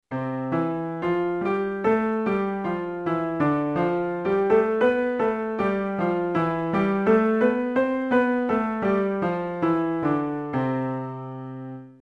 There is an entire array of songs in different styles that can be played upon the piano, depending on the creativity skill level of the pianist.
C Scale Exercise Traditional 0:12